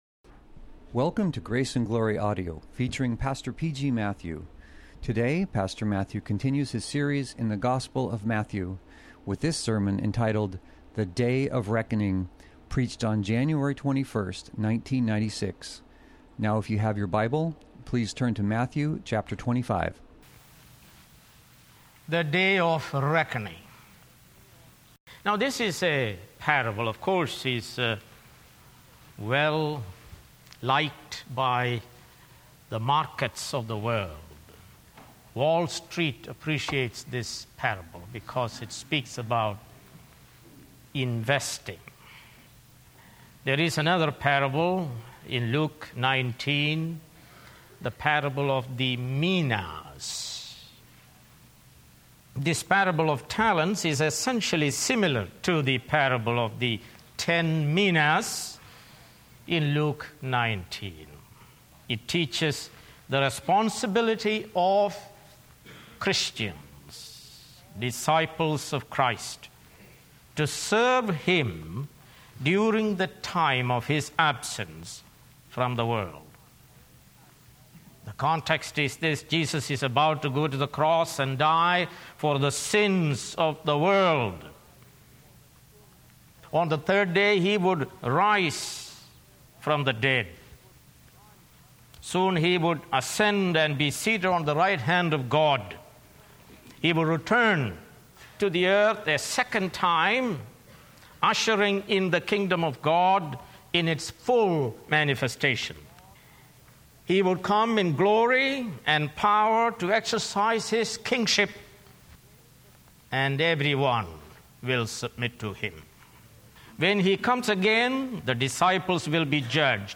Sermons | Grace Valley Christian Center | Page 2